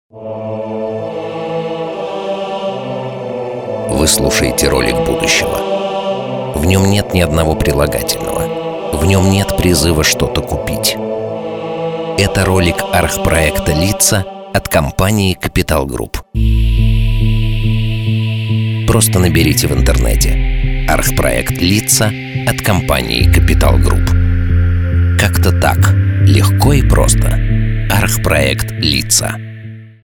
Radio Commercials